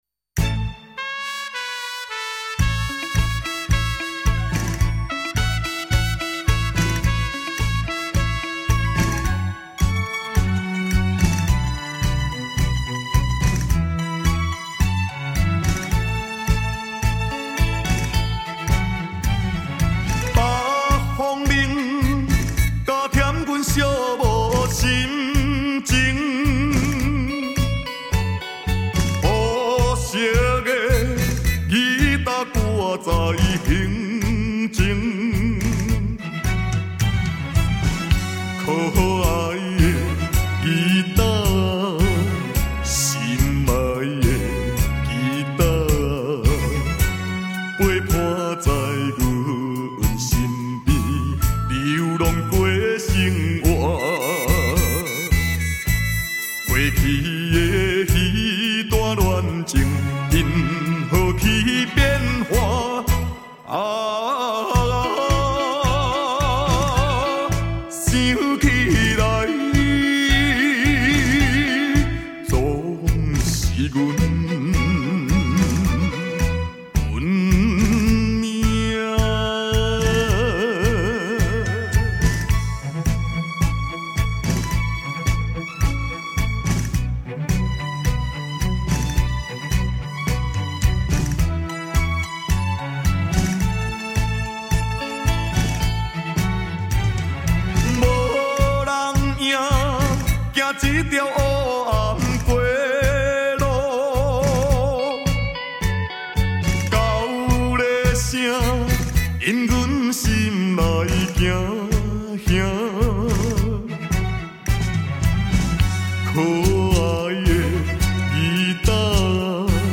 他那充满感情凄凉的歌声令人印象深刻。